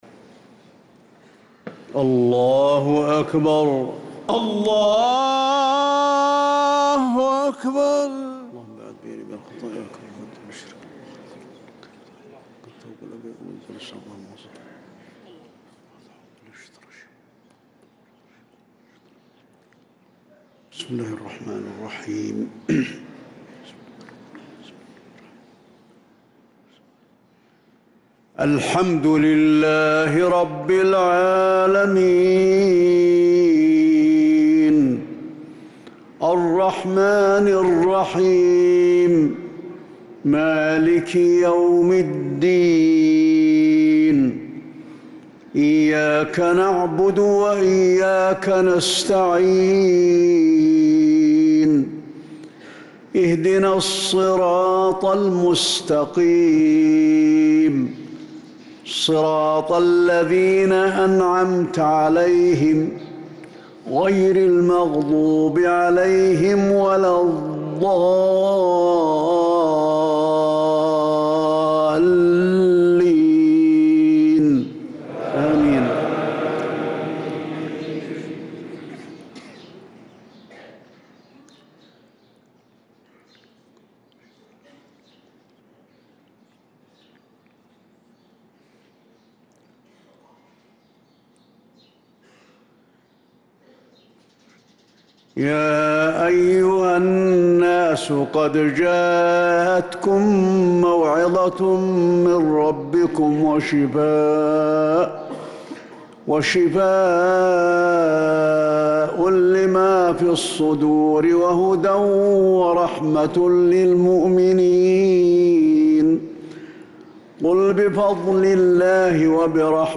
مغرب السبت 2-8-1446هـ من سورة يونس 57-65 | Maghrib prayer from Surat Yunus 1-2-2025 > 1446 🕌 > الفروض - تلاوات الحرمين